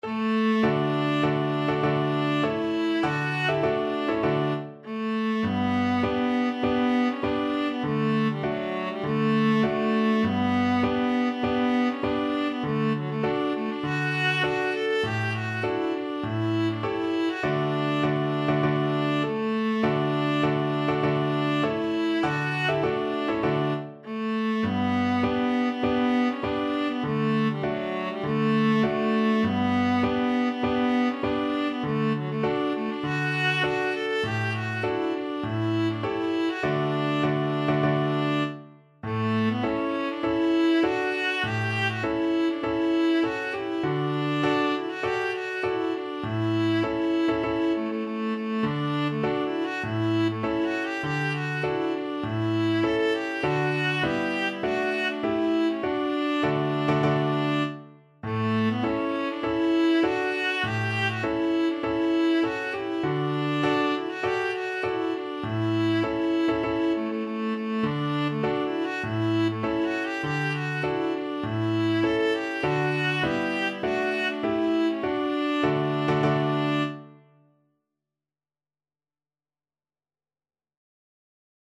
Viola
4/4 (View more 4/4 Music)
D major (Sounding Pitch) (View more D major Music for Viola )
Classical (View more Classical Viola Music)